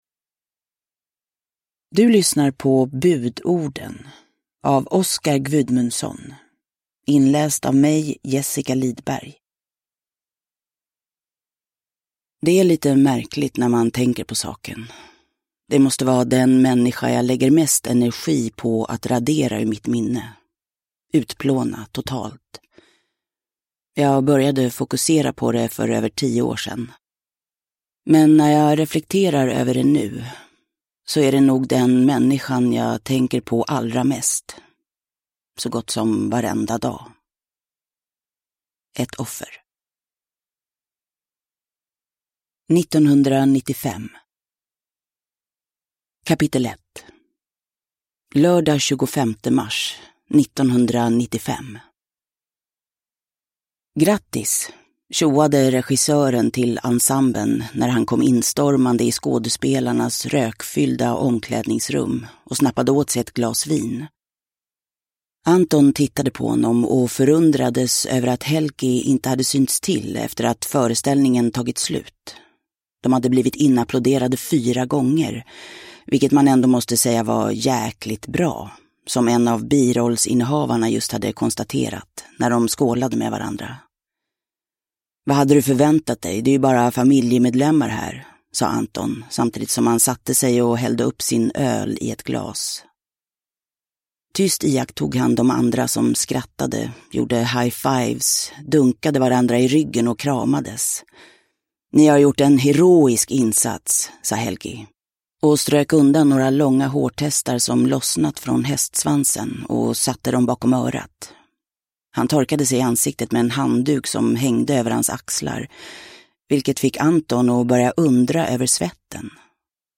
Budorden – Ljudbok – Laddas ner
Uppläsare: Jessica Liedberg